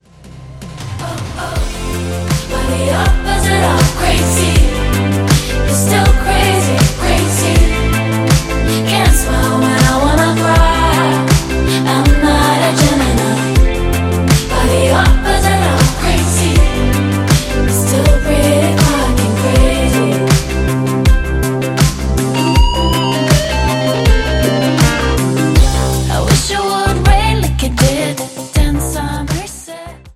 80年代のディスコ、ファンク、ポップス〜に影響を受けたファンキーな楽曲が2枚のヴァイナルに詰まったスペシャルな内容です！